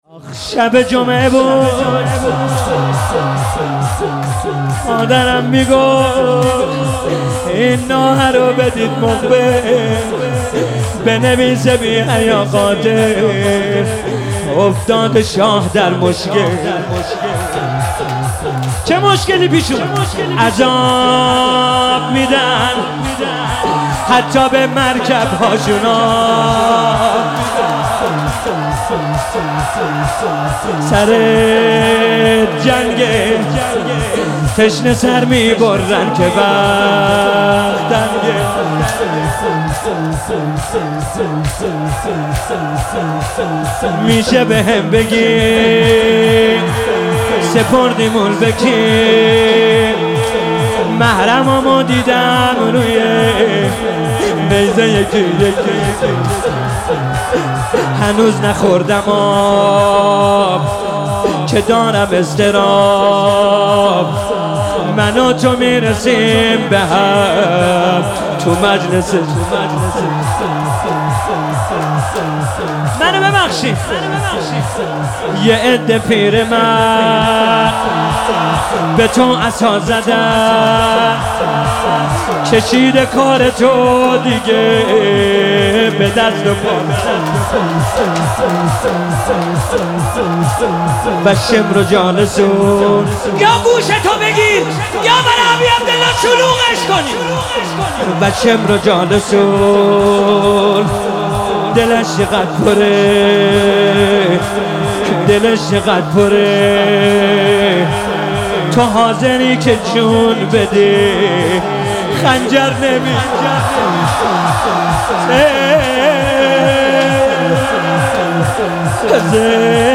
شب دوم عزاداری دهه دوم - شور- شب جمعه بود - محمد رضا طاهری
شب دوم عزاداری دهه دوم